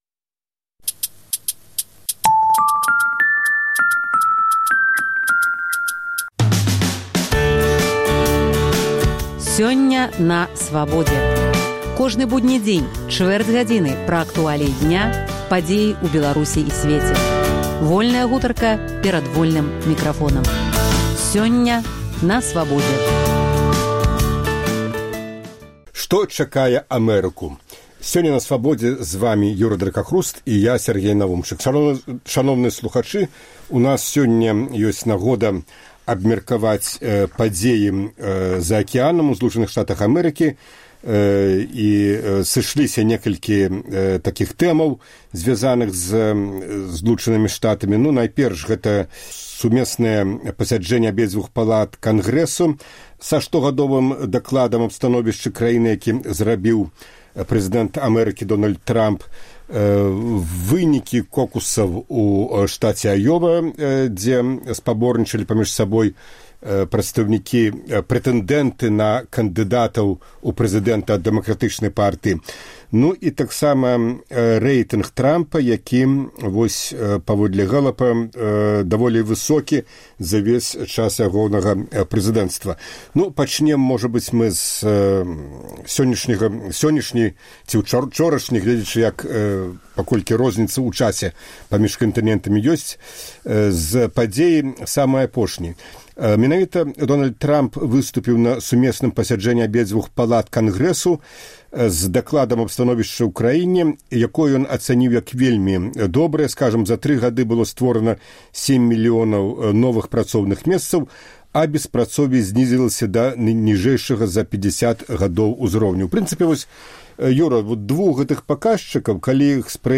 “Інтэрвію тыдня”